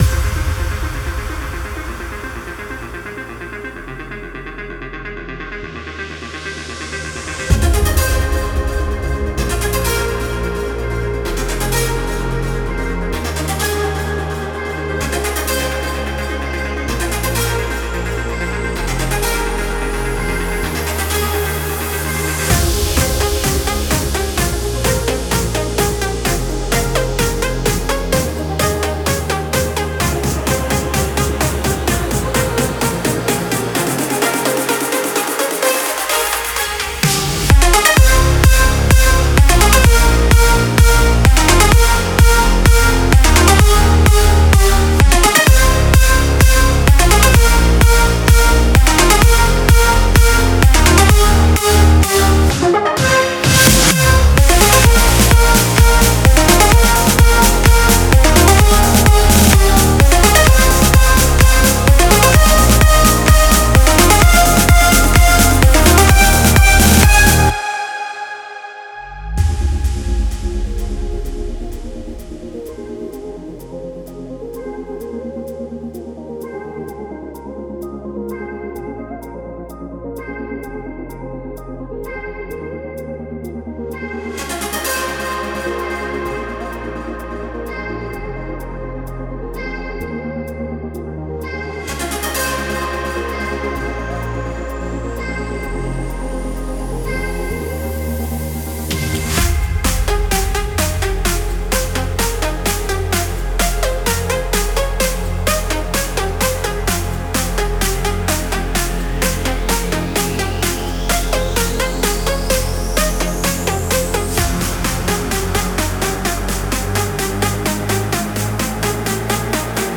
энергичная танцевальная композиция
позитивное и заряжающее